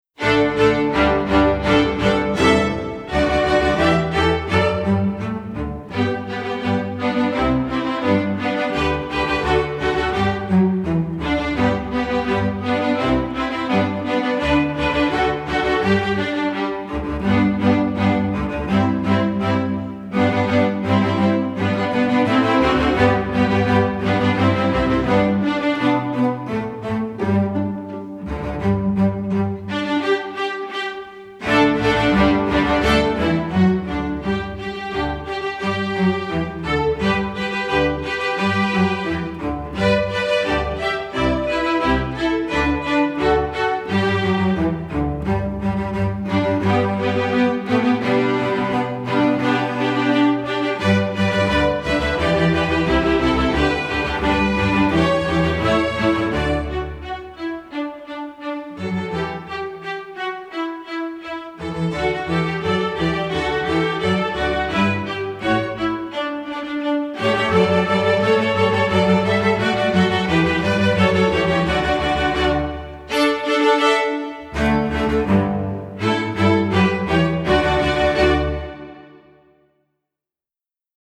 Instrumental Orchestra String Orchestra
String Orchestra